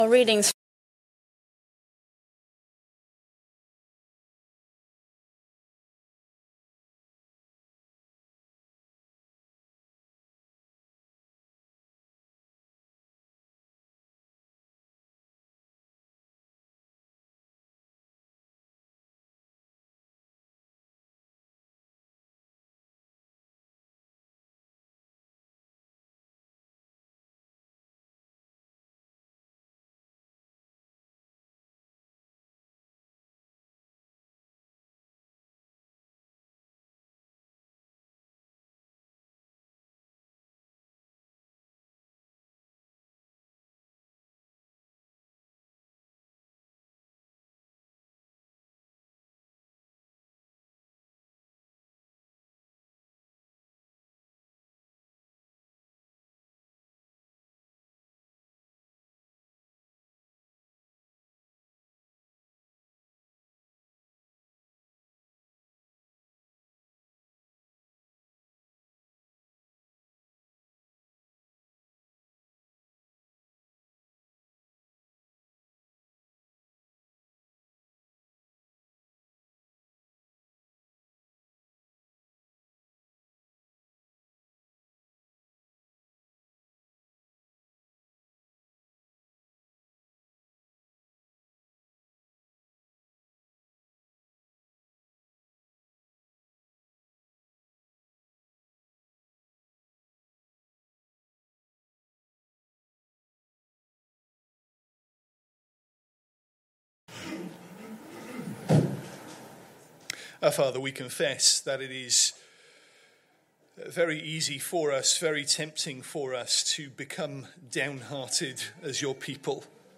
Sunday Morning Service Sunday 17th August 2025 Speaker